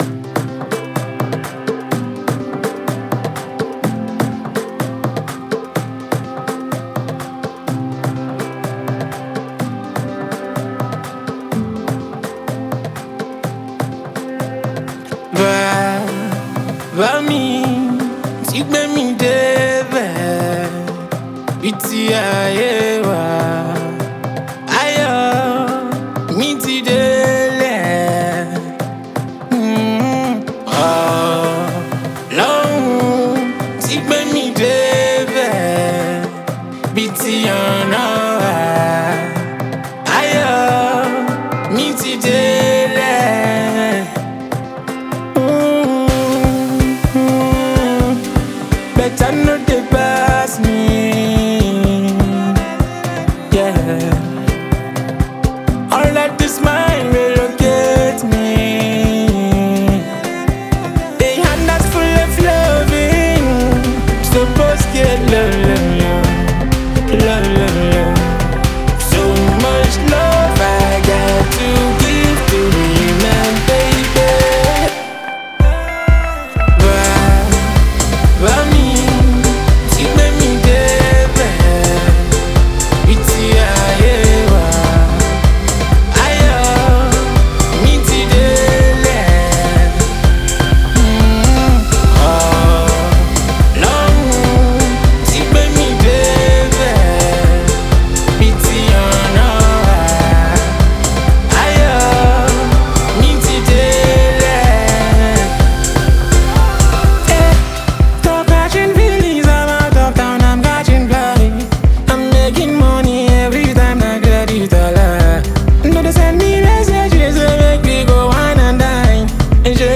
Talented Nigerian singer and songwriter
soulful and uplifting record
With its catchy rhythm and heartfelt delivery